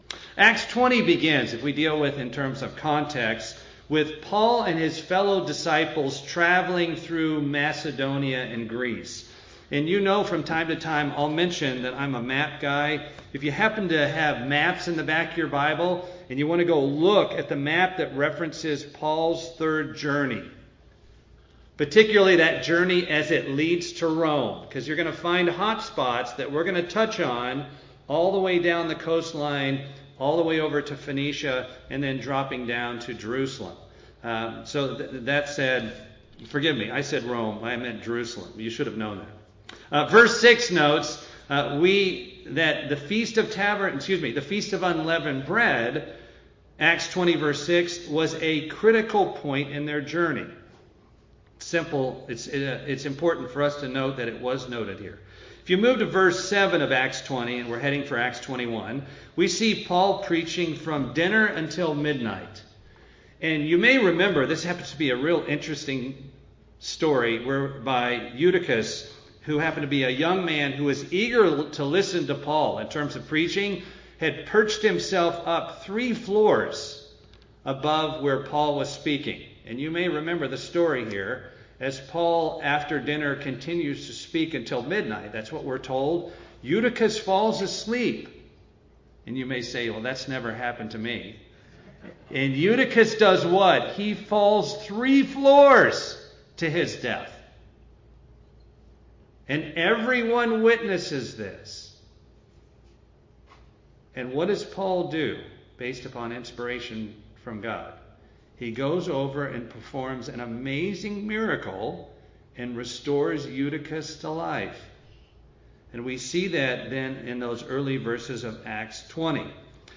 In a continuation of a series of sermons given on the Books of Acts